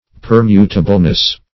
Per*mut"a*ble*ness, n. -- Per*mut"a*bly, adv.